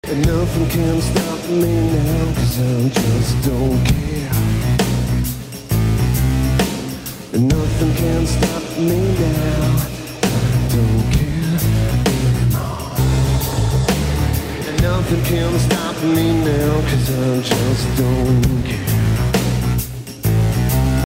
live in stunning black-and-white